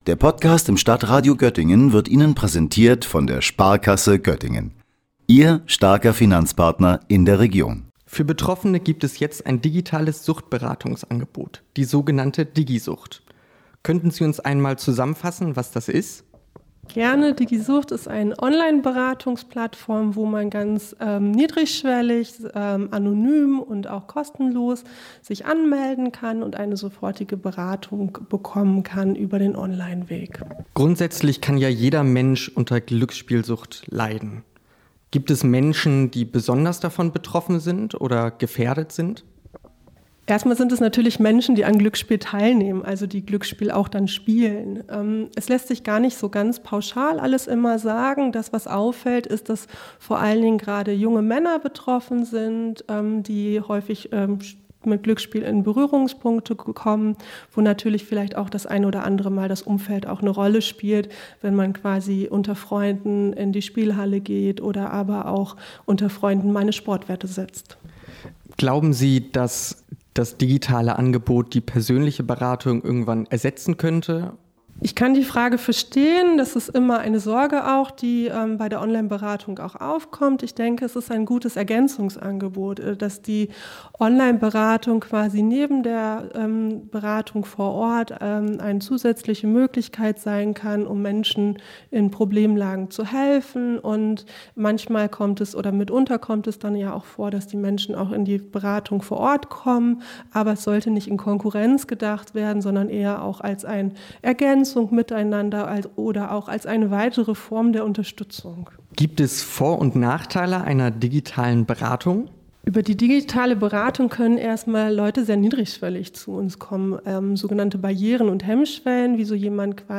Anlässlich des heutigen bundesweiten Aktionstags der „Glücksspielsucht“ macht die Fachstelle für Sucht und Suchtprävention des Diakonieverbandes Göttingen-Münden auf die digitale Suchtberatung aufmerksam. Im Gespräch